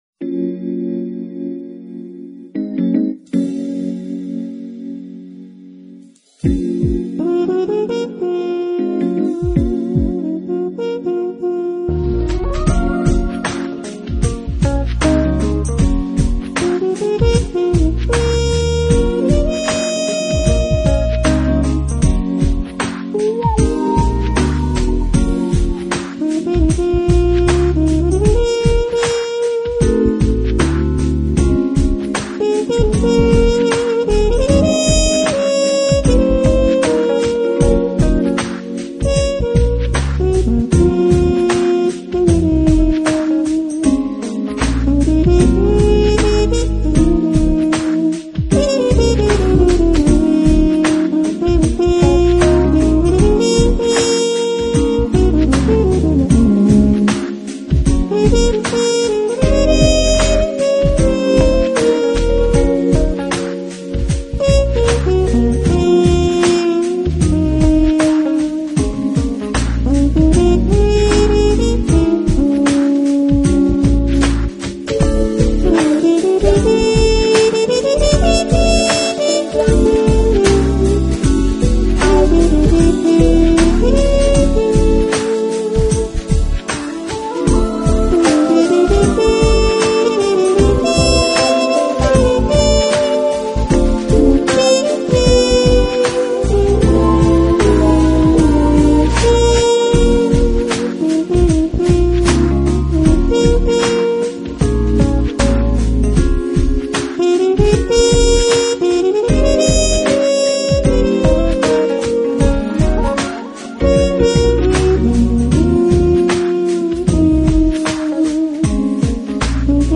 音乐类型：Smooth Jazz